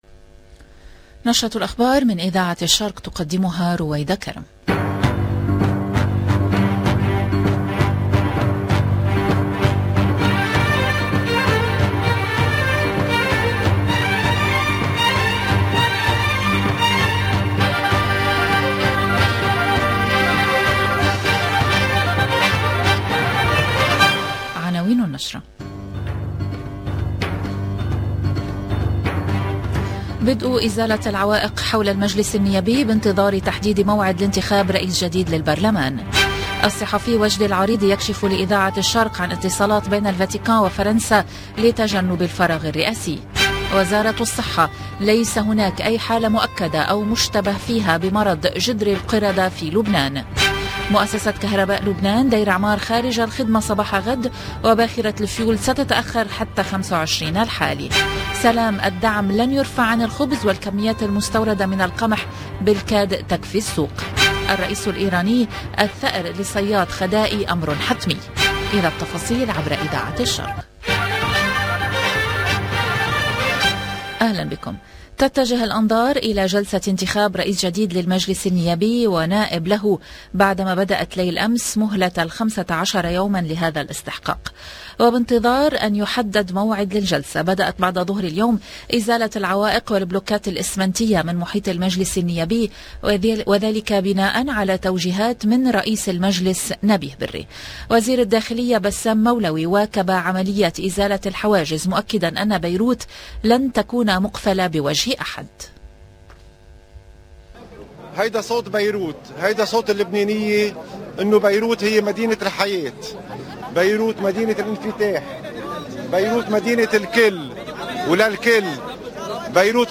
LE JOURNAL DU LIBAN DU SOIR DU 23/05/22